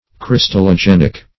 crystallogenic.mp3